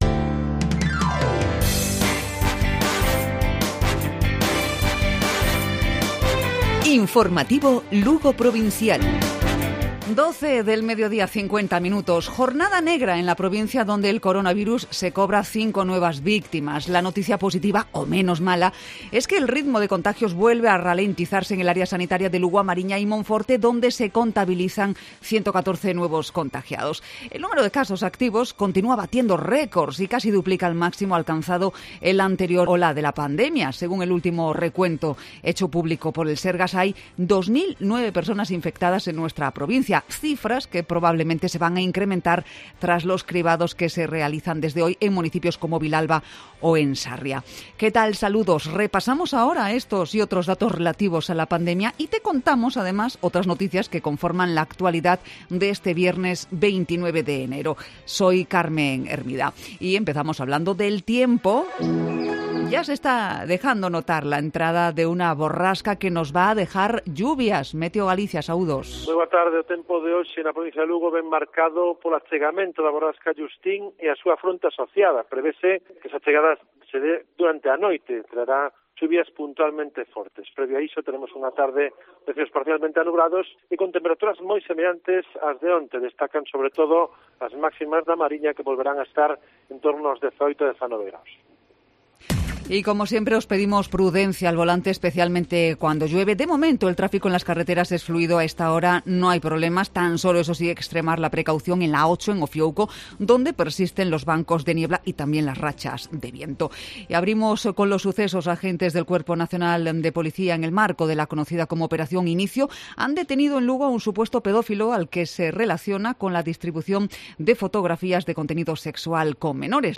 Informativo Provincial Cope Lugo. Viernes, 29 de enero. 12:50 horas.